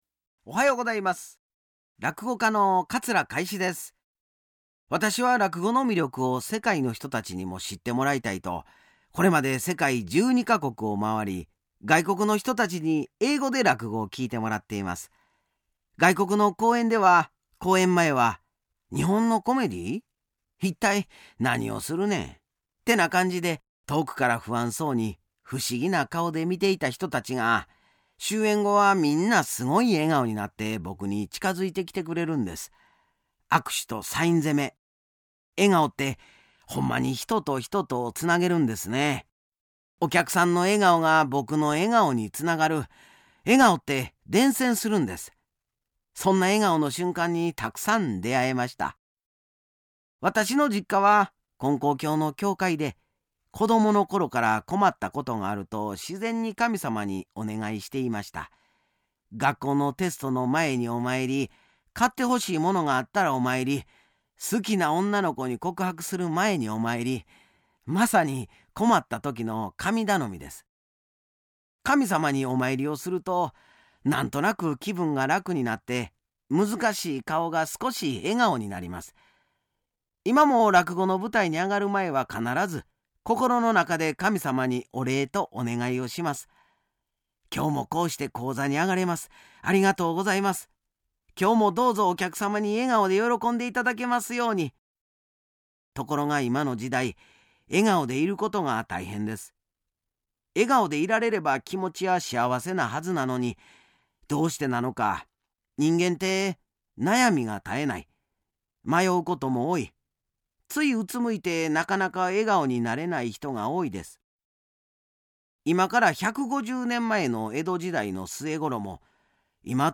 ●立教150年記念特別番組
（出演：桂 かい枝）